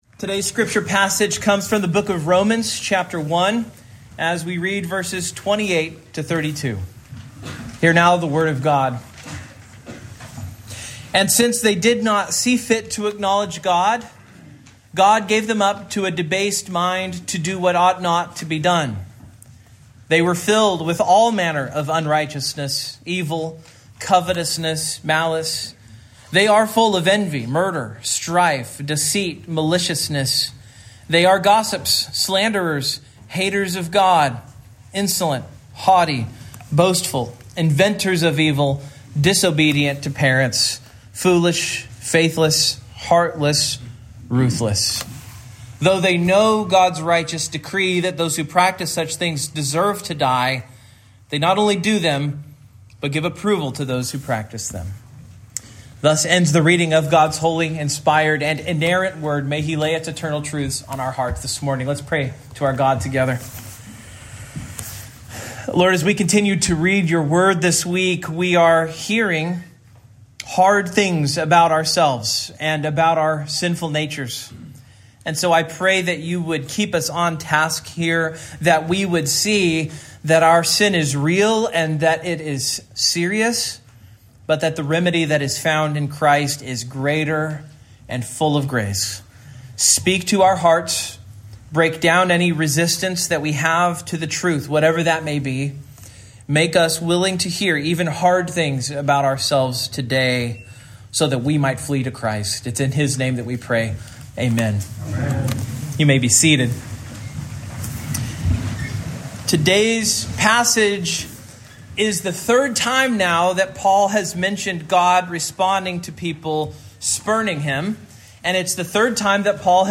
Romans 1:28-32 Service Type: Morning Main Point